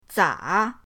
za3.mp3